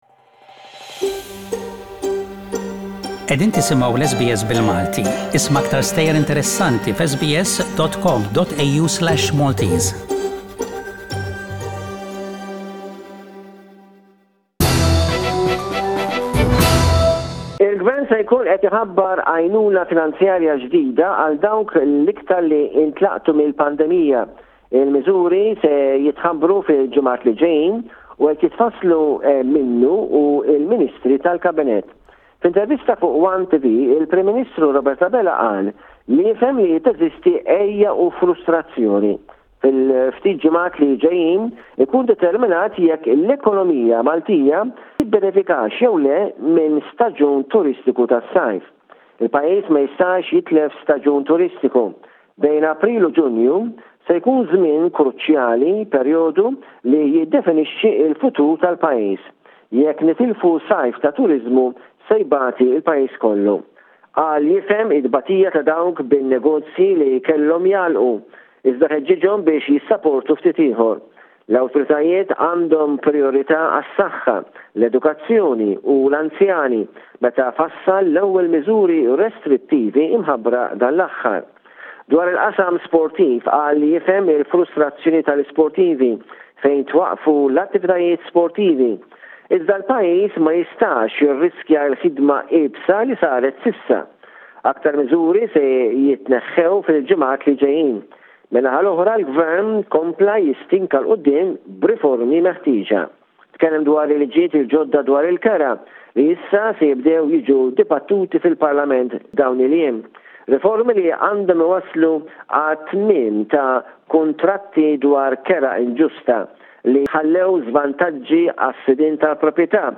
SBS Radio correspondent